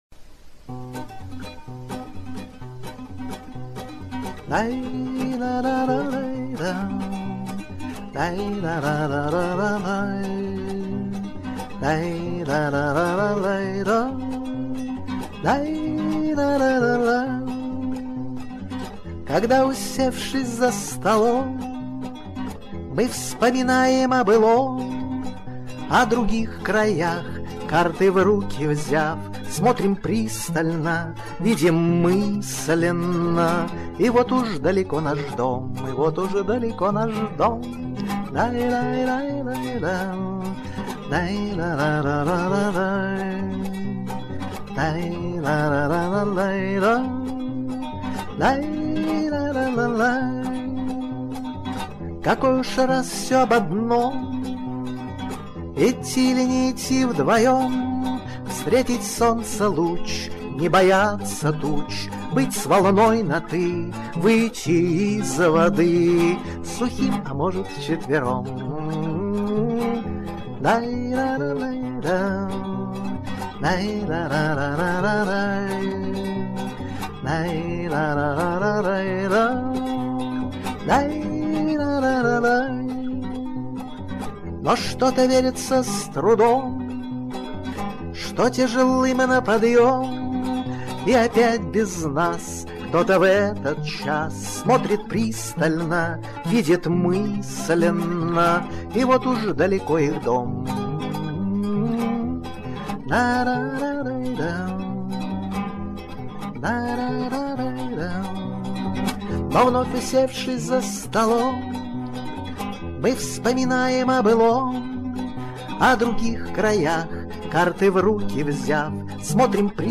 Пение, гитара